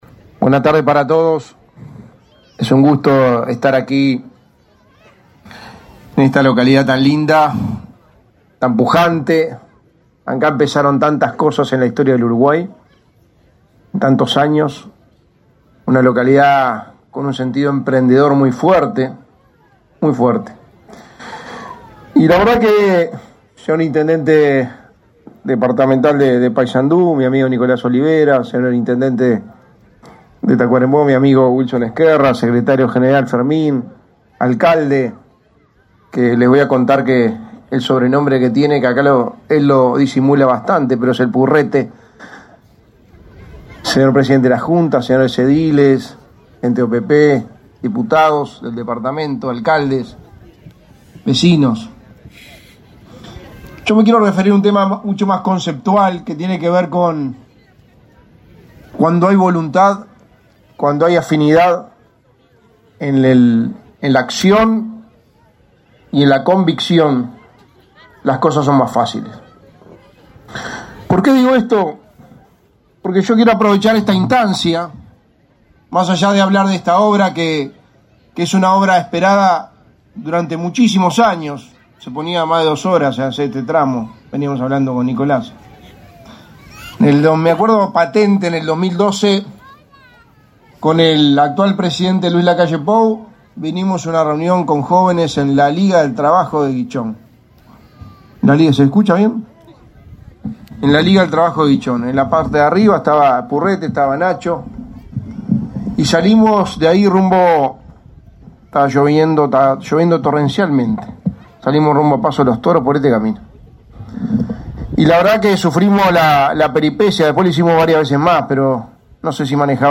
Palabras del secretario de la Presidencia, Álvaro Delgado
Con la presencia del secretario de la Presidencia de la República, Álvaro Delgado, se realizó, este 26 de agosto, la inauguración obras de
Palabras del secretario de la Presidencia, Álvaro Delgado 26/08/2023 Compartir Facebook X Copiar enlace WhatsApp LinkedIn Con la presencia del secretario de la Presidencia de la República, Álvaro Delgado, se realizó, este 26 de agosto, la inauguración obras de pavimentación en camino Piñera-Merino- Morató, en el departamento de Paysandú.